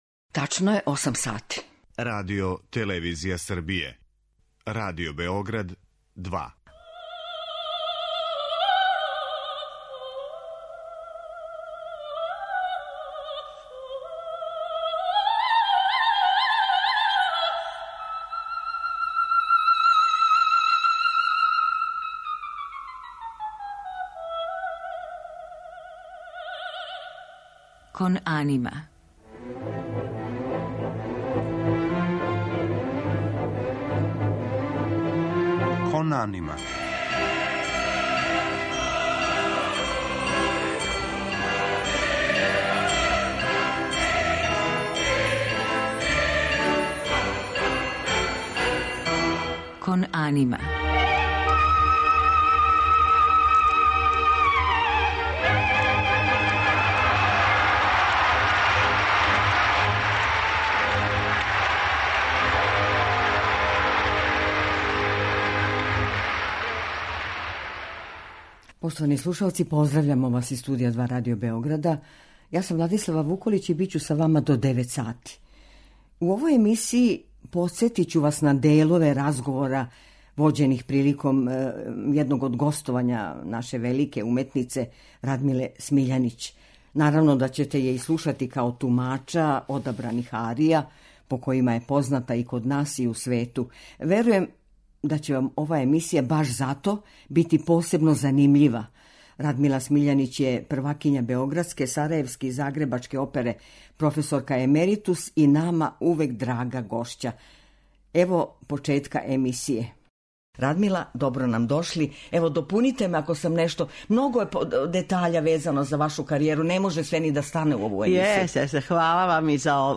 Гошћа у емисији: Радмила Смиљанић
У музичком делу биће емитоване арије из опера Ђакома Пучинија, Рихарда Вагнера, Ђузепа Вердија, Беджиха Сметане, Дмитрија Шостаковича, као и изузетно леп дует из Трубадура , у којем јој је партнер њен син, иначе познати баритон Никола Мијаиловић.